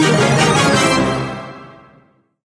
laser_upgrade_char_01.ogg